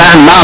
Play, download and share bambamloud original sound button!!!!
bam-bam-loud.mp3